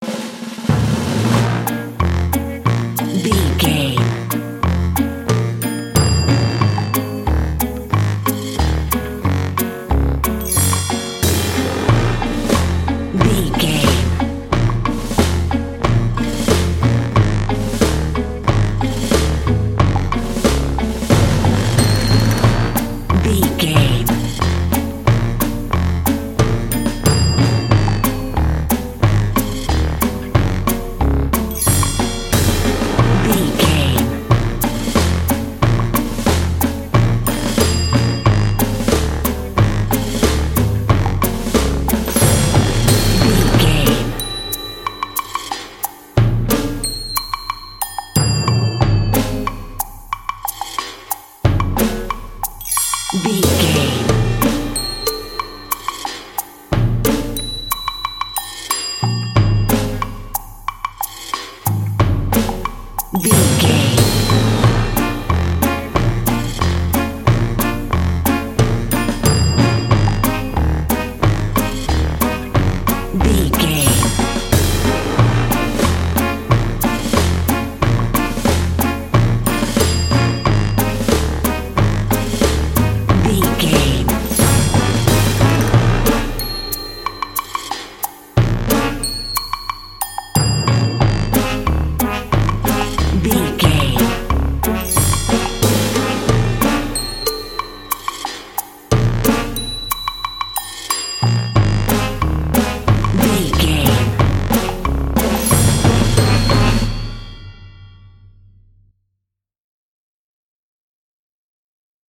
Aeolian/Minor
B♭
percussion
synthesiser
horns
strings
silly
circus
goofy
comical
cheerful
perky
Light hearted
quirky